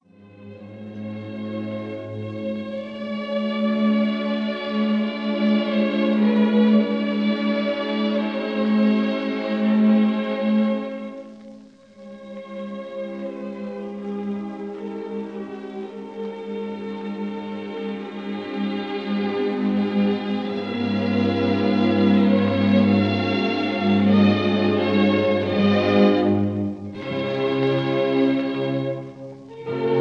Historic 1931 recording